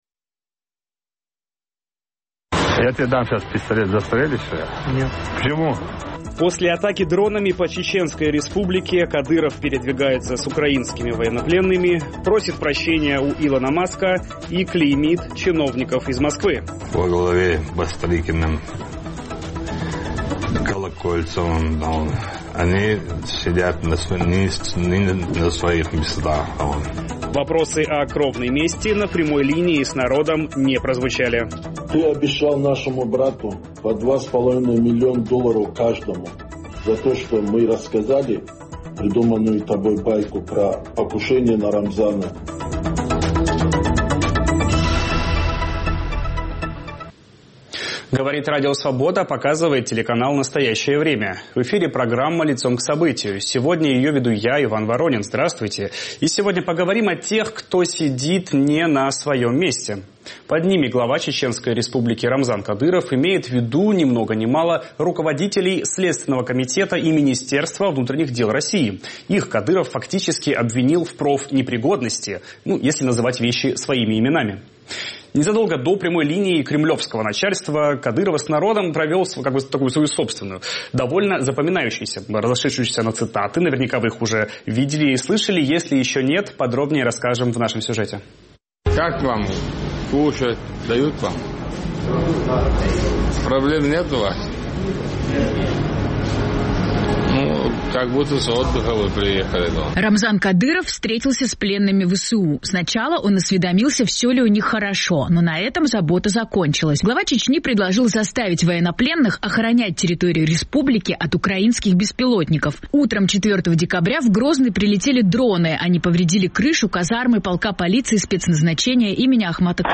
Дойдет ли конфликт Кадырова с главами СК и МВД до того, что глава Чечни запишет извинение и перед ними? Насколько сейчас сильны позиции Рамзана Кадырова в российской политике? Об этом говорим с правозащитником из Чечни